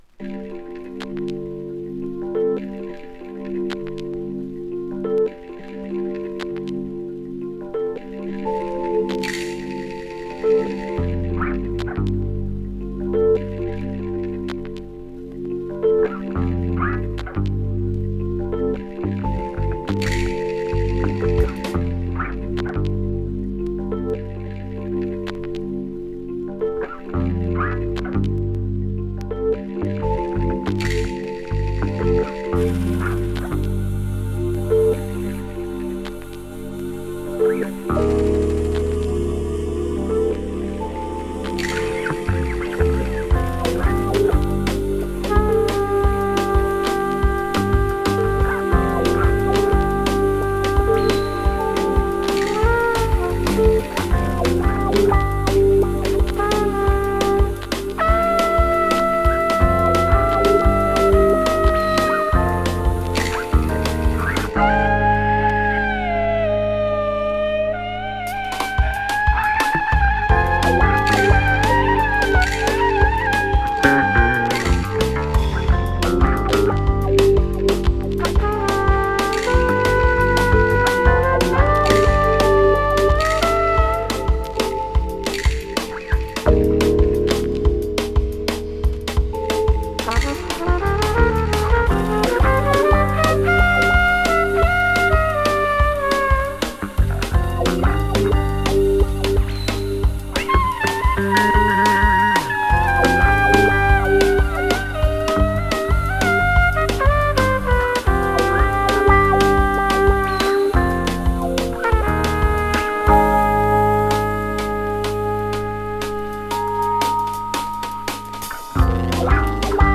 ジャズをメインにしたリミックスの数々。